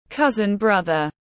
Anh em họ tiếng anh gọi là cousin brother, phiên âm tiếng anh đọc là /ˈkʌz.ənˌbrʌð.ər/.
Cousin brother /ˈkʌz.ənˌbrʌð.ər/